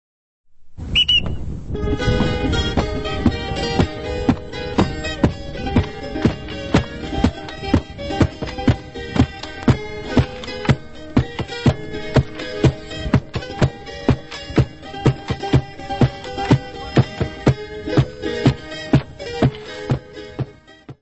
Series:  (Portuguese Folk Music; 4)
Music Category/Genre:  World and Traditional Music